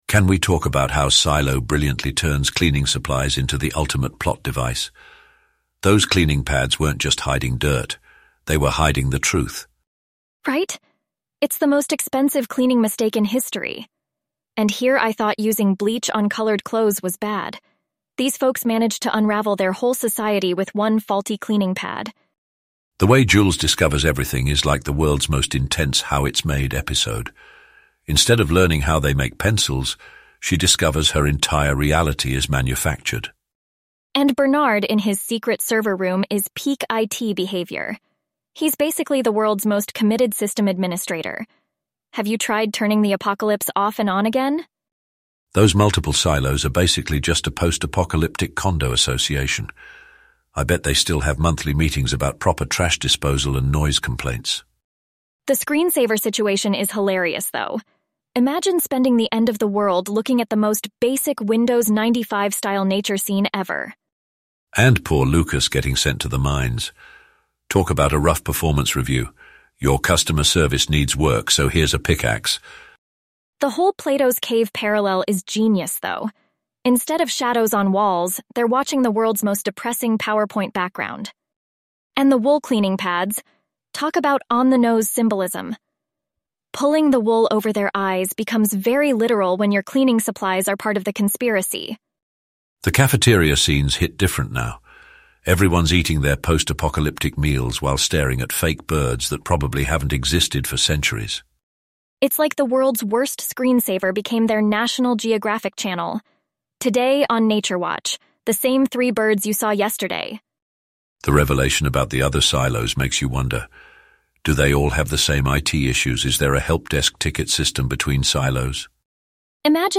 AI podcast summary from a youtube video using Anthropic or XAI and Elevenlabs voices